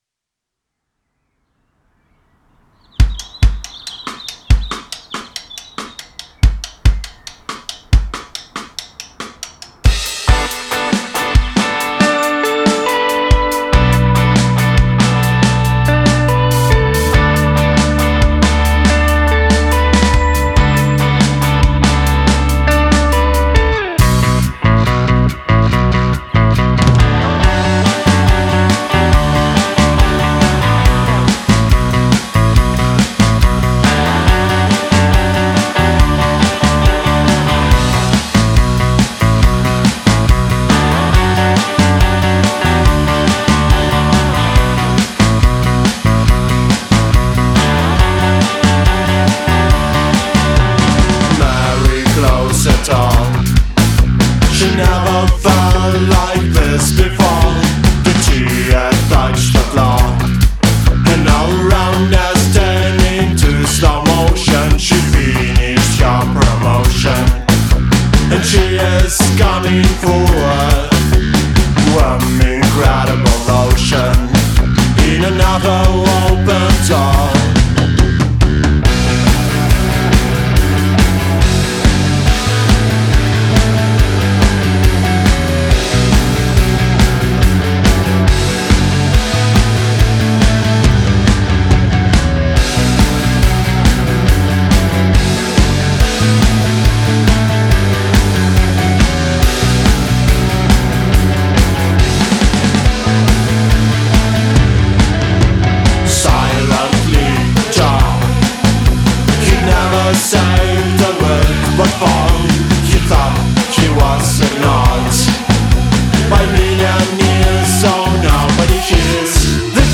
Демка- Mary
Про девку Mary, Инструментов минимально и Vox один -был,всё живое,гитары к сожалению (а может и нет) прислали только DI. Хоум творчество.